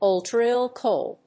オルトレ・イル・コッレ